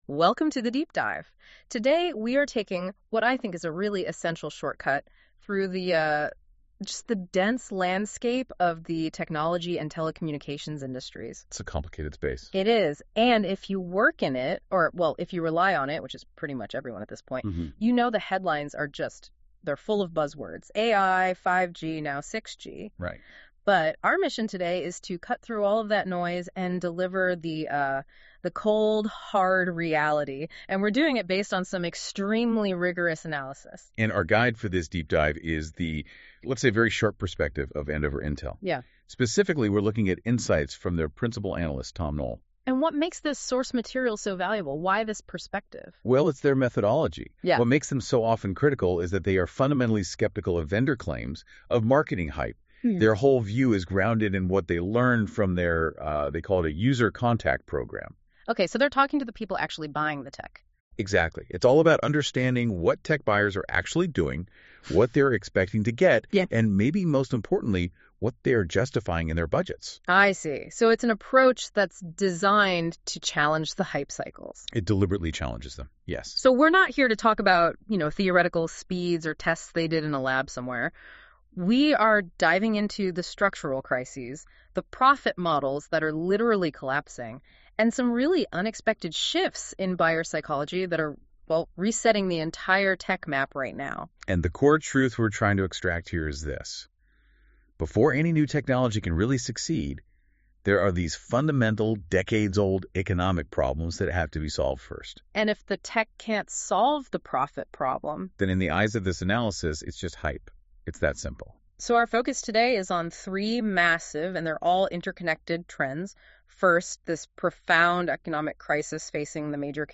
As usual, it’s a virtual podcast created simply by importing those blogs and asking for an analysis. The final result has not been edited in any way.